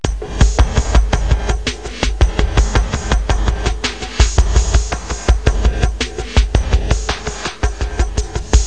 00455_Sound_808beats